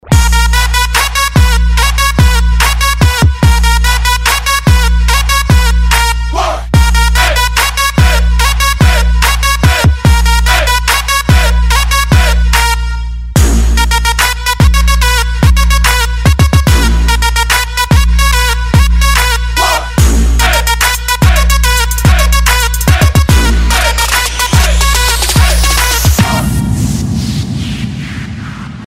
• Качество: 256, Stereo
Bass